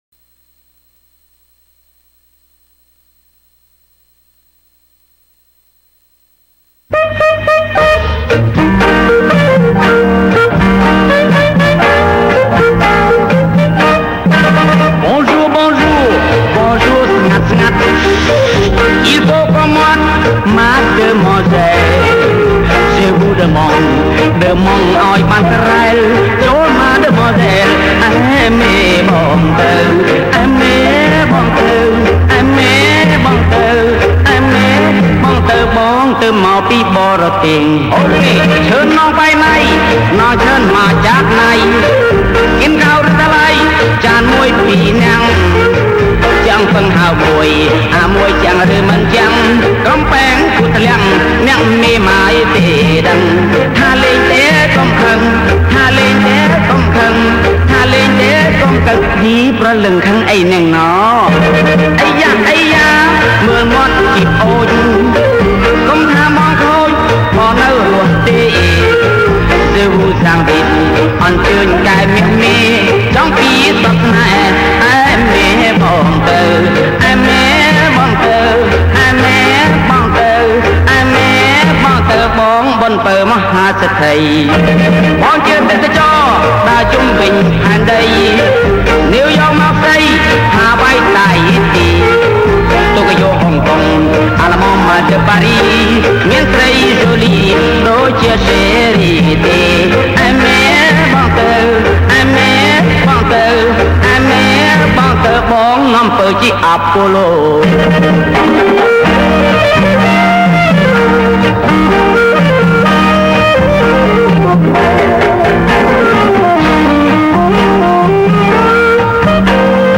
ថាស Vinyl
45 RPM